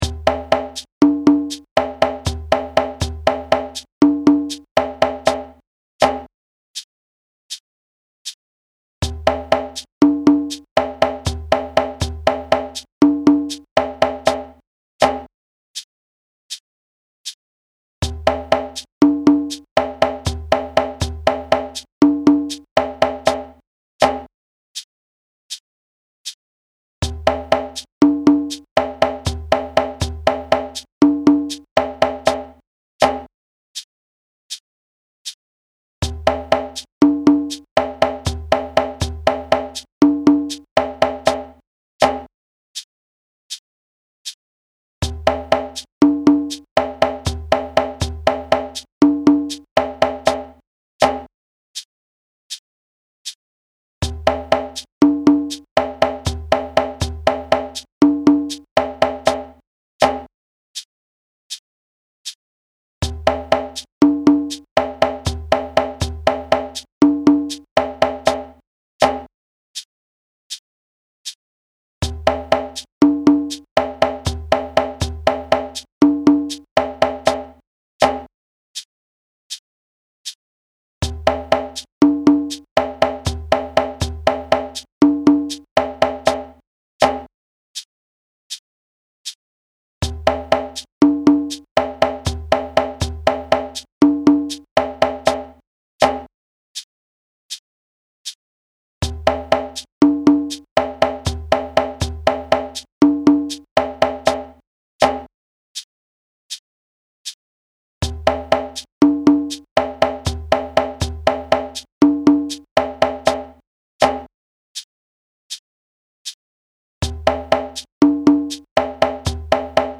This phrase represents a typical 2-bar phrase found in traditional djembe music.
audio (with shekeré)
This impressive collection of 2-Bar Special Phrases (60) are drawn from djembe solo settings and were chosen for their inherent “call and response” qualities as well as for their ability to teach the drummer how to dance around the pulse.